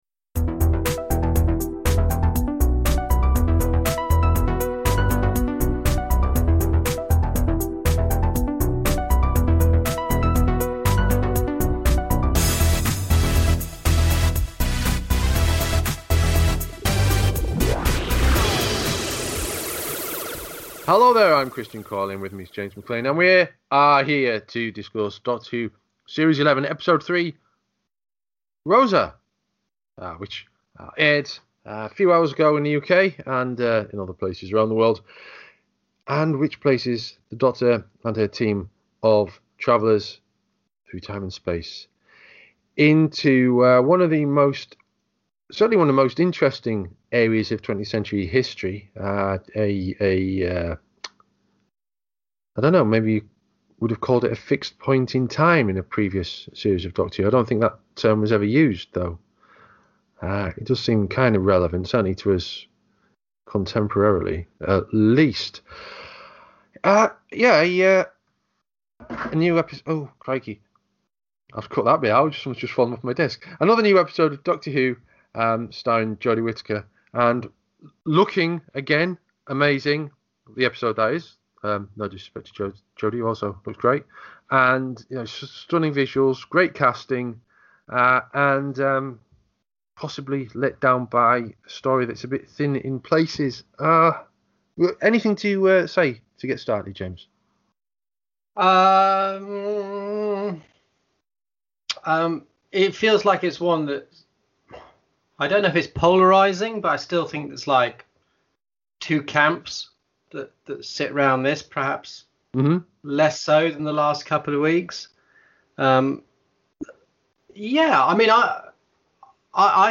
middle aged white men